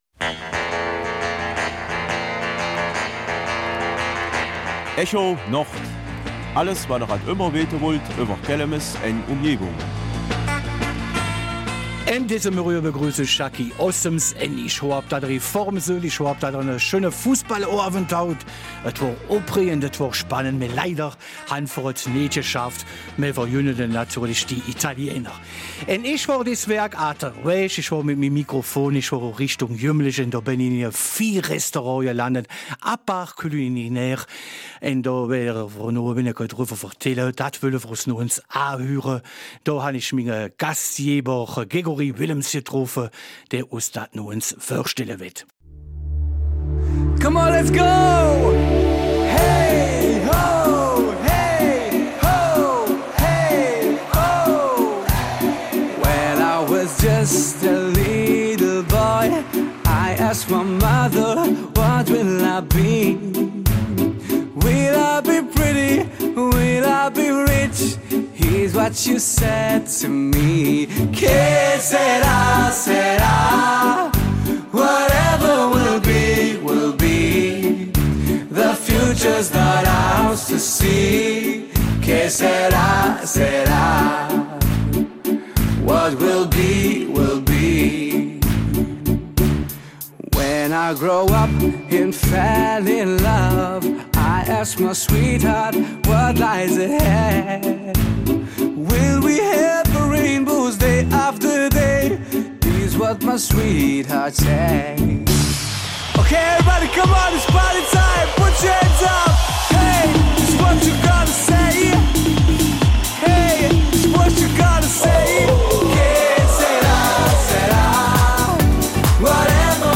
Kelmiser Mundart: Restaurant ''Appart Culinaire''